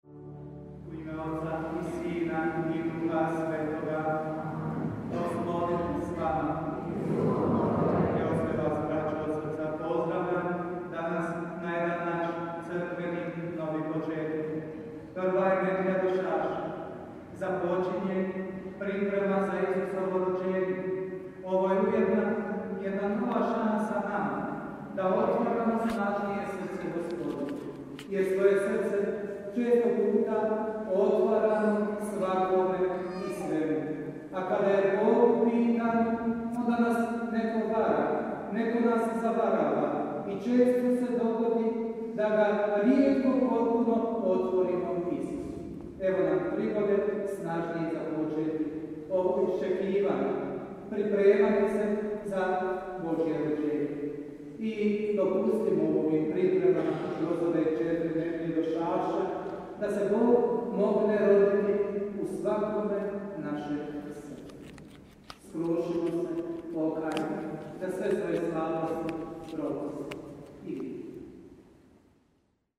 POČETAK sv. MISE